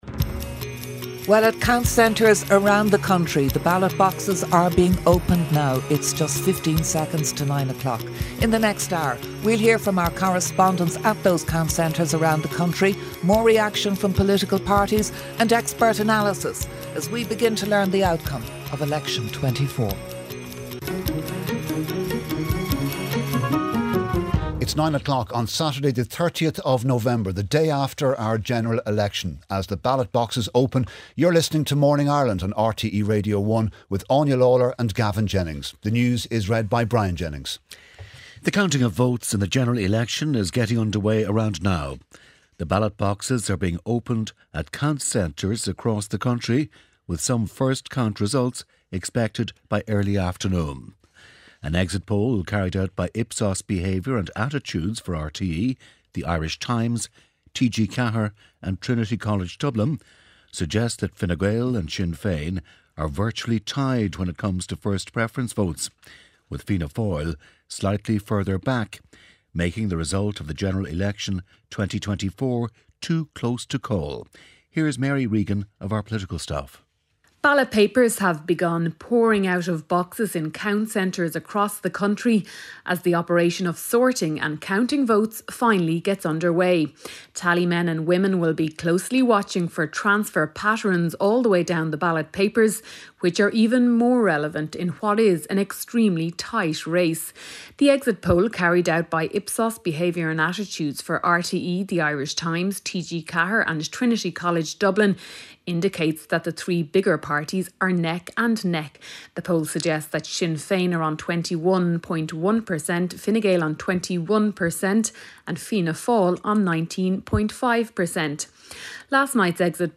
RTÉ's flagship news and current affairs radio programme and the most listened-to show in Ireland, featuring the latest news and analysis with Gavin Jennings, Audrey Carville, Áine Lawlor and Mary Wilson.